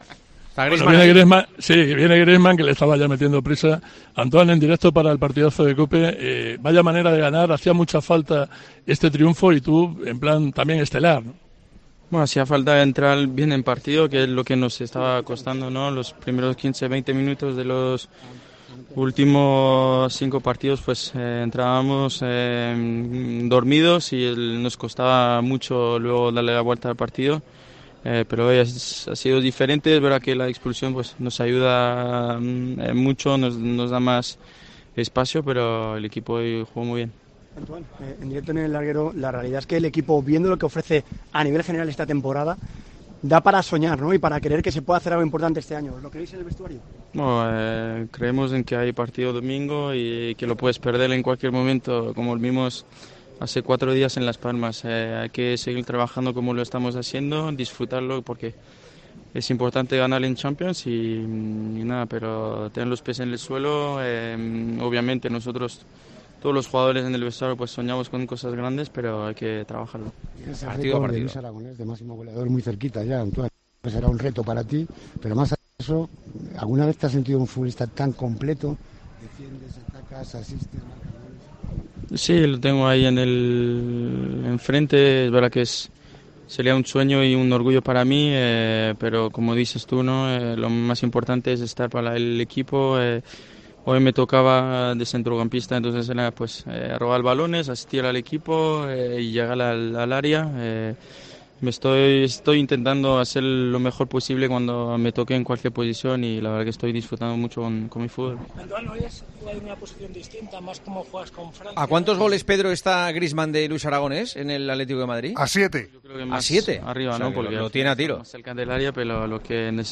El delantero del Atlético de Madrid habló de la histórica goleada de los rojiblancos, que ganaron 6-0 al Celtic y son líderes de su grupo de Champions.